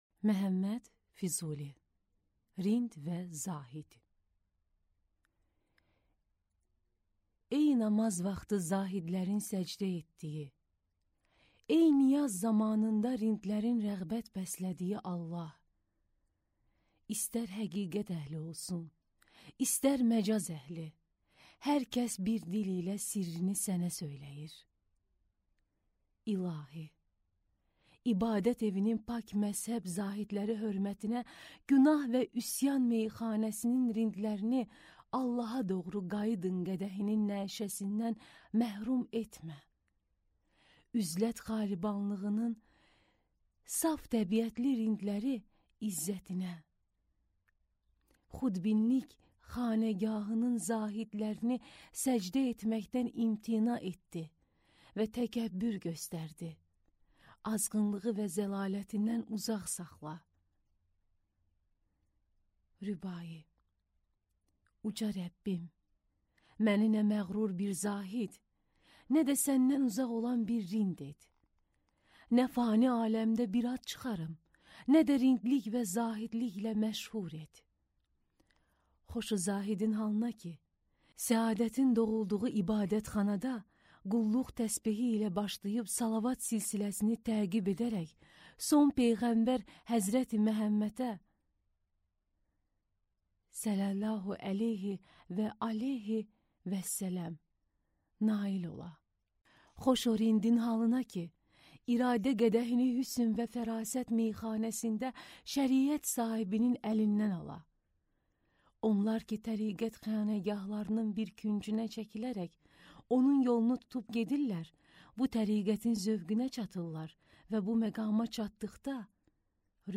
Аудиокнига Rind və Zahid | Библиотека аудиокниг
Прослушать и бесплатно скачать фрагмент аудиокниги